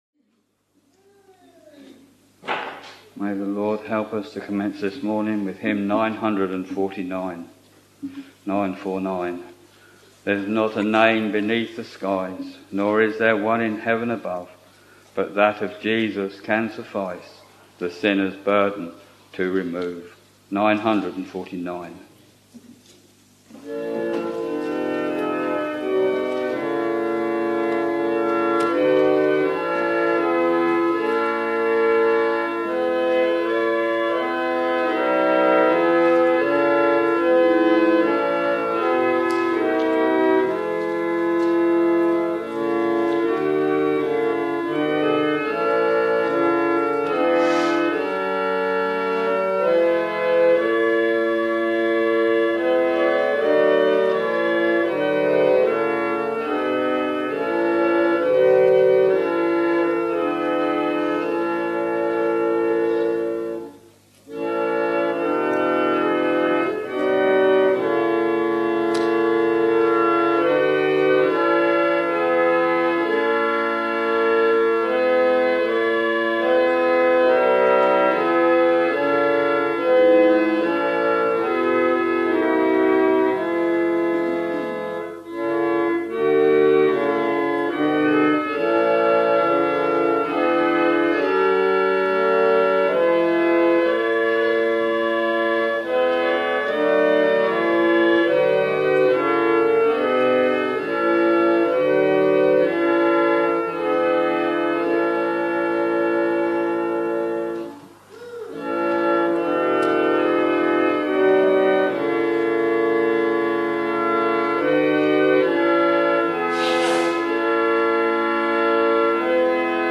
Sunday, 18th April 2021 — Morning Service Preacher